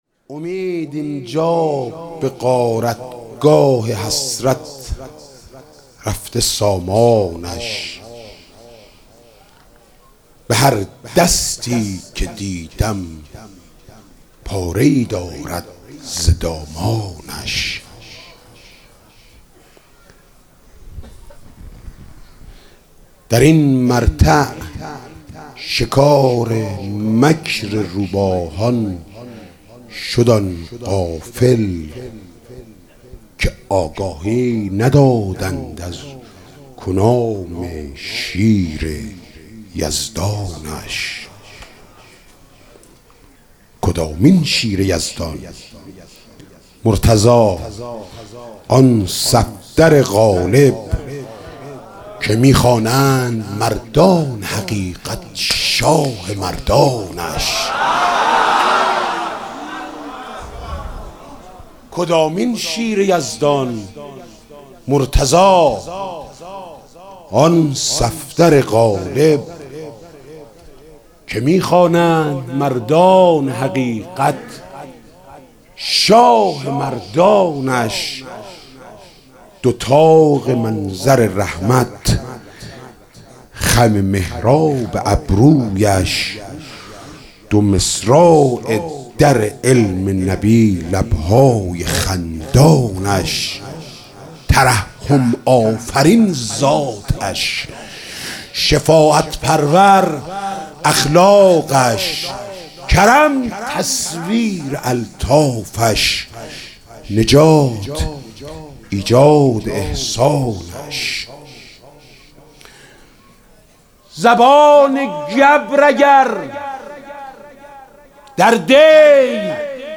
مدح: امید اینجا به غارتگاه حسرت رفته سامانش